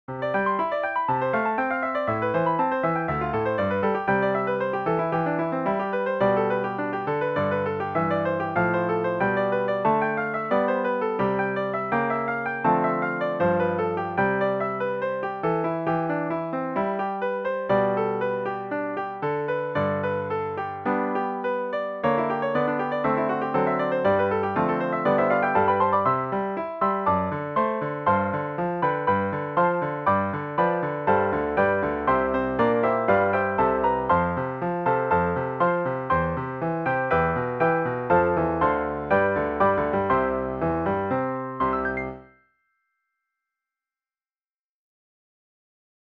PIANO SOLO Patriotic, American Music Skill level
DIGITAL SHEET MUSIC - PIANO SOLO